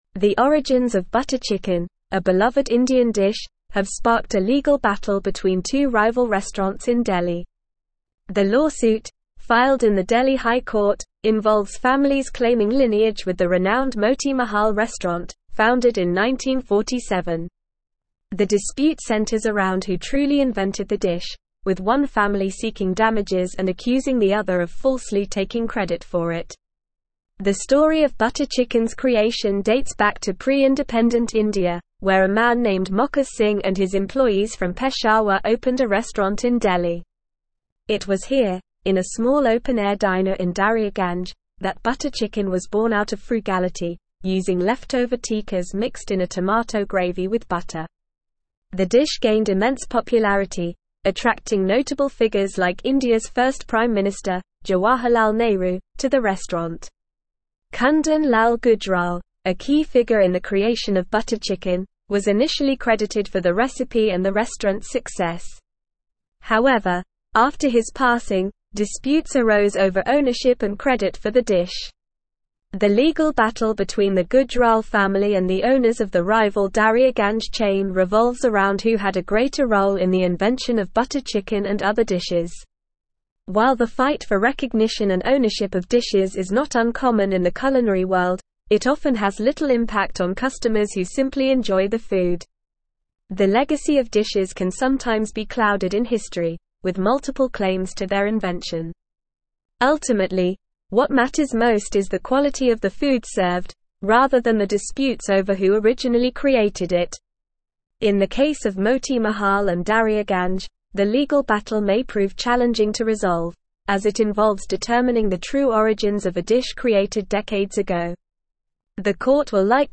Normal
English-Newsroom-Advanced-NORMAL-Reading-Butter-Chicken-Origins-Delhi-Restaurants-in-Legal-Battle.mp3